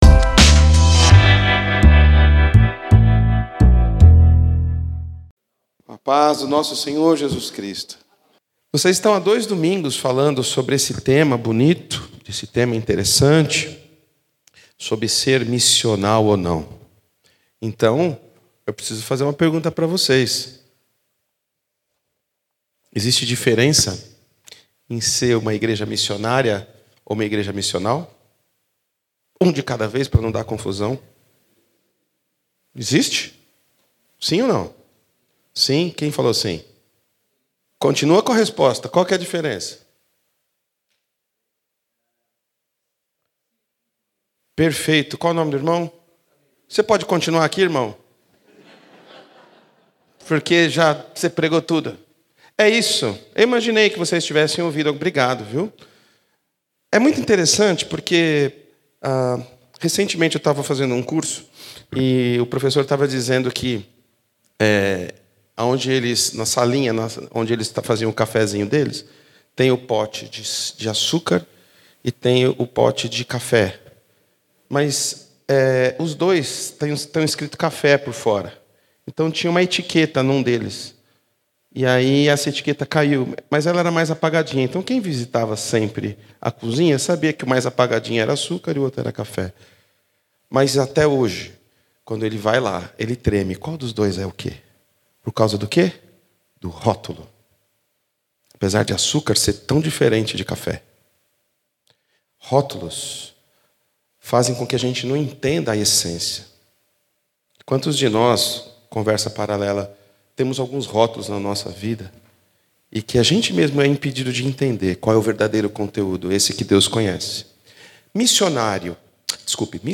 Conferência Missionária de 2022.